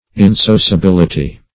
Search Result for " insociability" : The Collaborative International Dictionary of English v.0.48: Insociability \In*so`cia*bil"i*ty\, n. [Cf. F. insociabilit['e].]